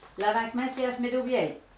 Localisation Saint-Christophe-du-Ligneron
Langue Maraîchin
Catégorie Locution